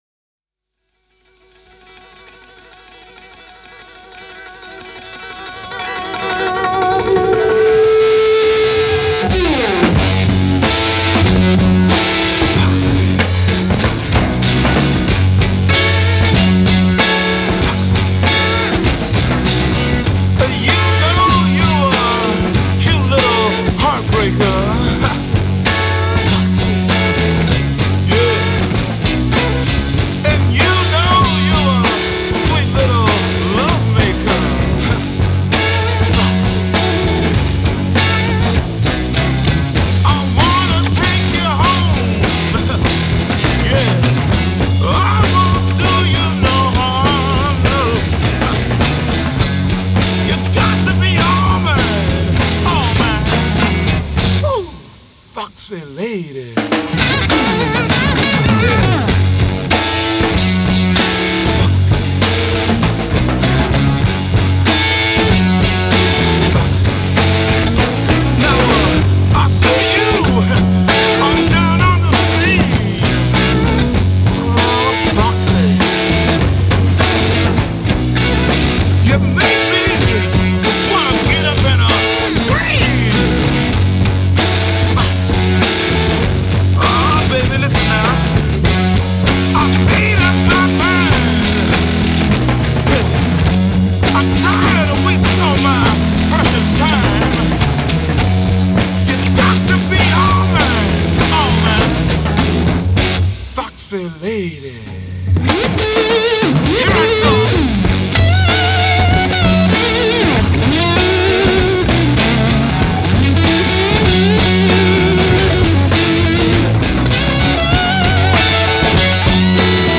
Psychedelic Rock سایکدلیک راک